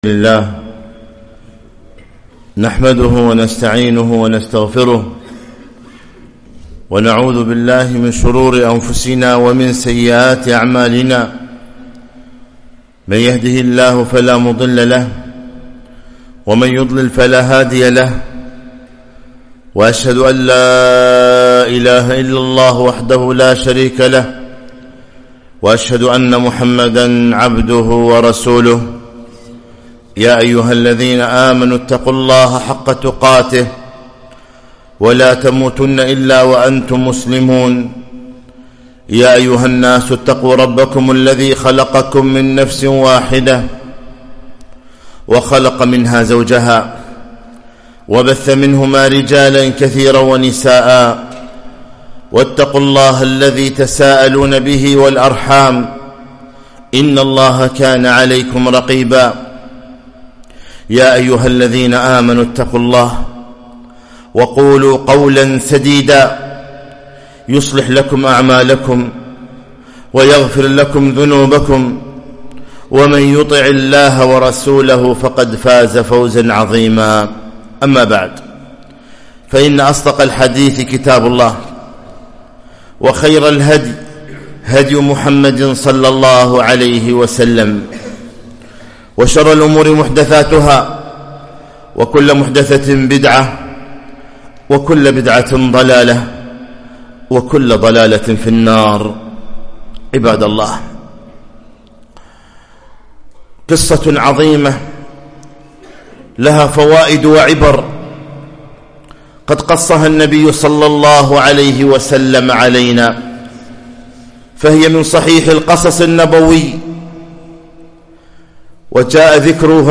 خطبة - قصة الملك والساحر والغلام